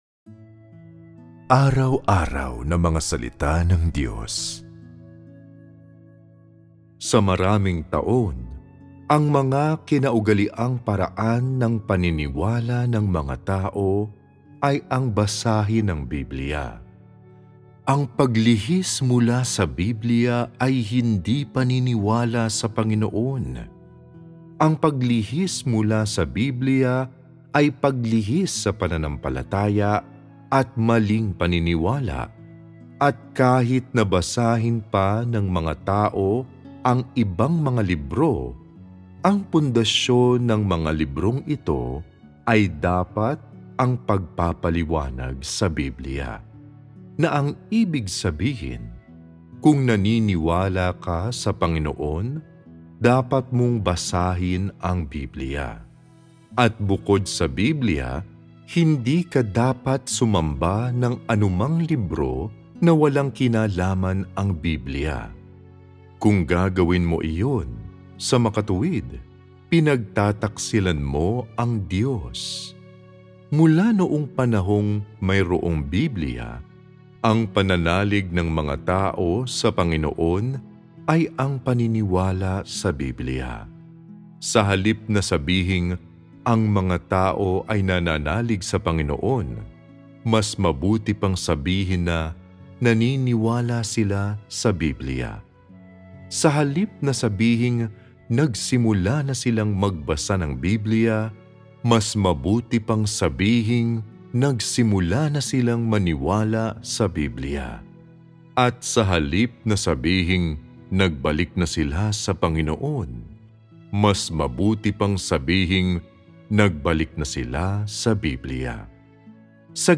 recital-mysteries-about-the-bible-265.m4a